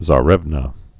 (zä-rĕvnə, tsä-)